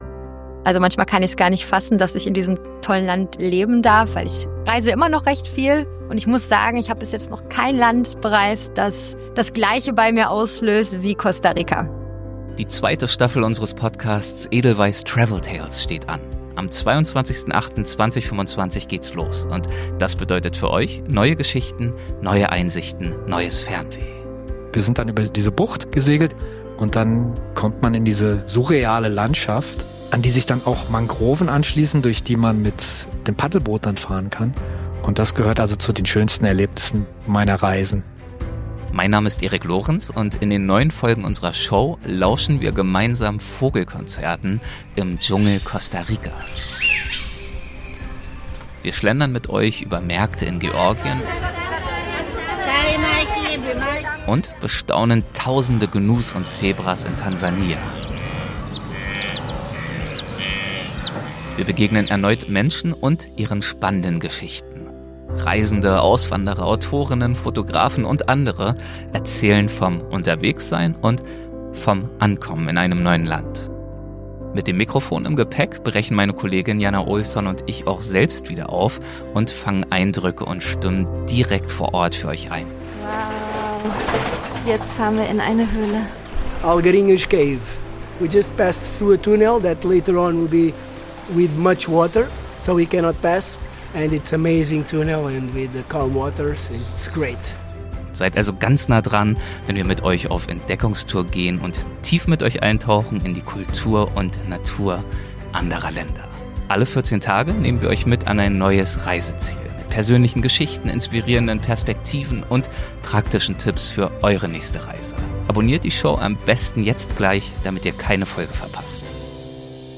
Staffel 2: Trailer
22.08.2025. Im Trailer hört Ihr bereits jetzt ein paar tolle